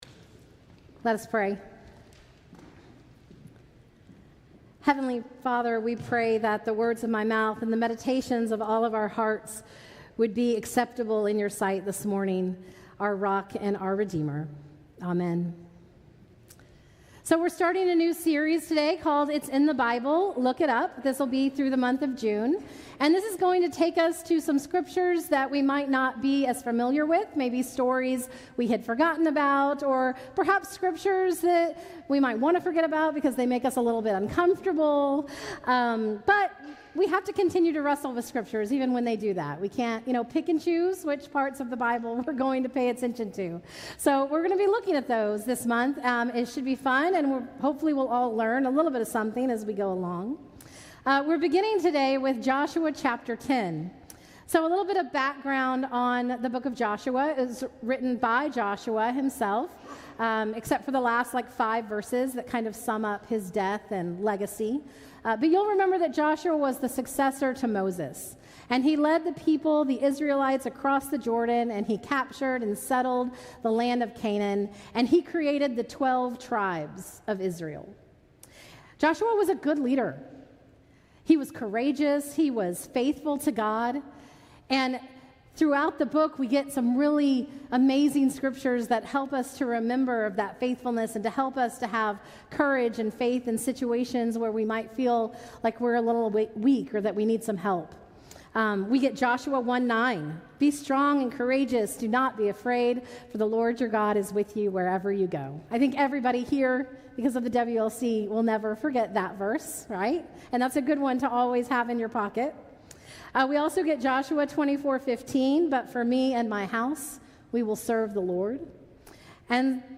Traditional-Service-—-Jun.-4-2023.mp3